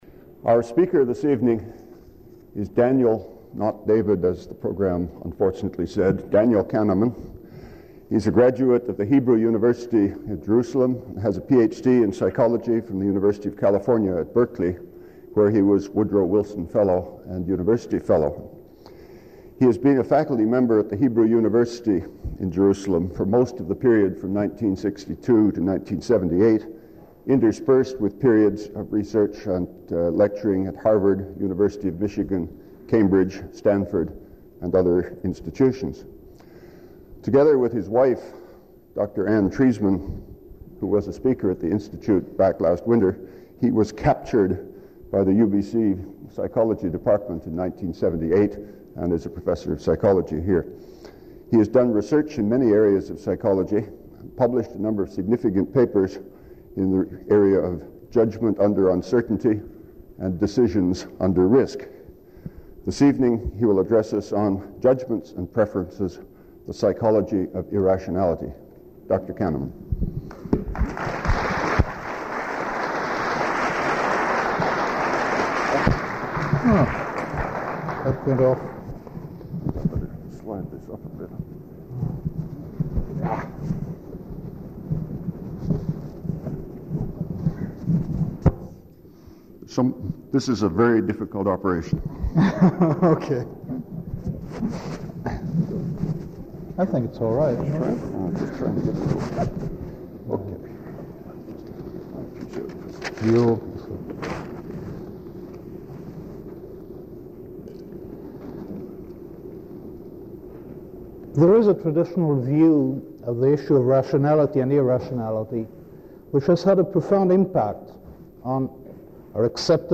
Item consists of a digitized copy of an audio recording of a Vancouver Institute lecture given by Daniel Kahneman on October 27, 1979.